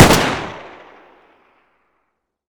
sako95_fire1.wav